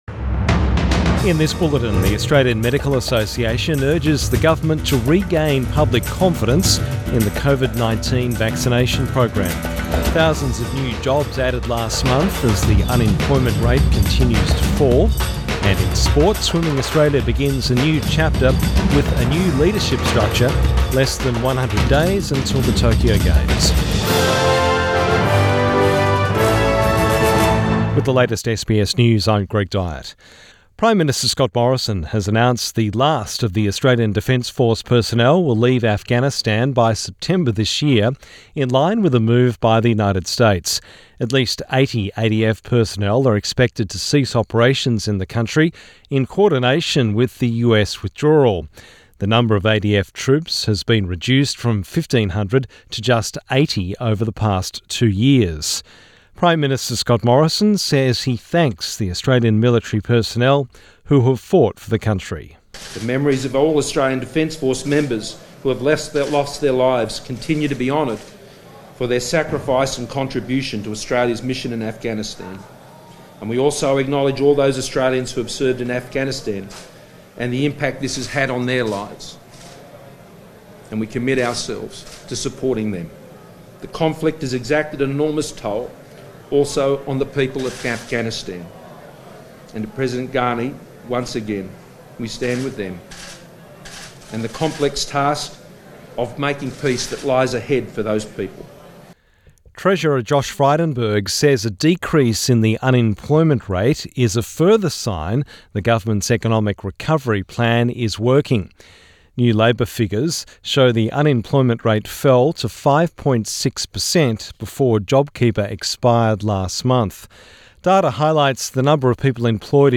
PM bulletin 15 April 2021